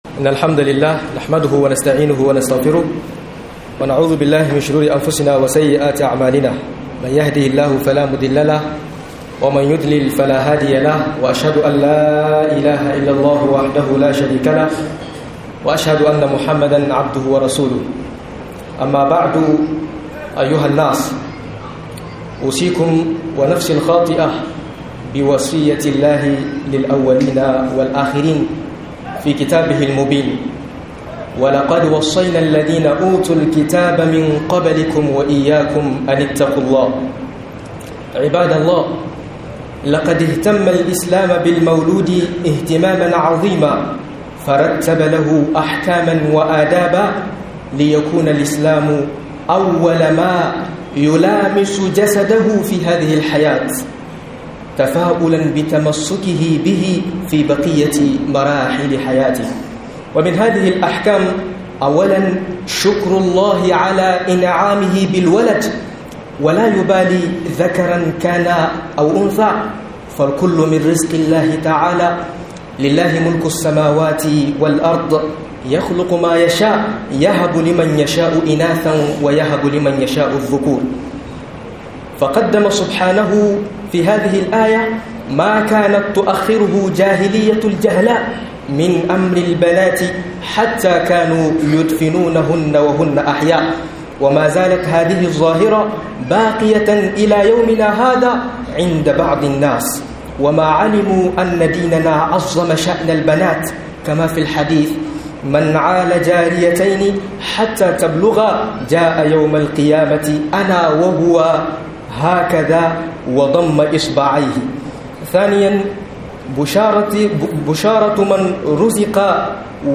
(suna) Hukunce hukuncen suna - MUHADARA